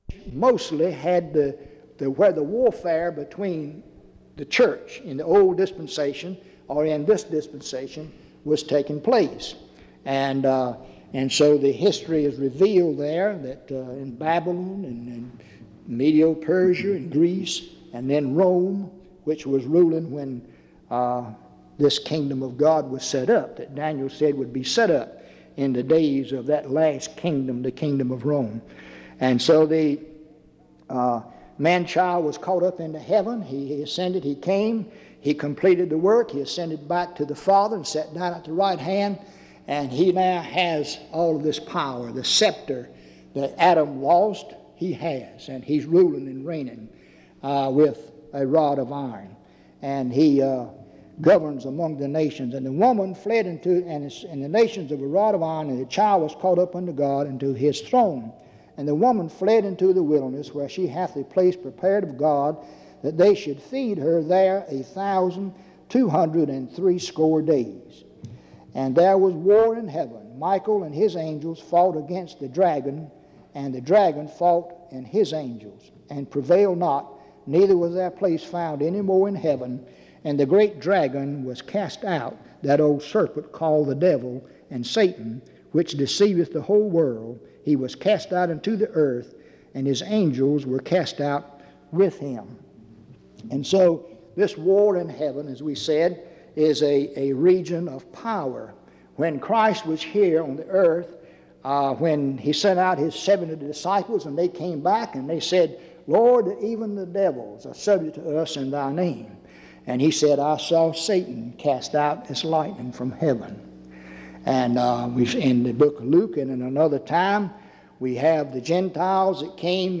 Dans Collection: Reidsville/Lindsey Street Primitive Baptist Church audio recordings La vignette Titre Date de téléchargement Visibilité actes PBHLA-ACC.001_003-A-01.wav 2026-02-12 Télécharger PBHLA-ACC.001_003-B-01.wav 2026-02-12 Télécharger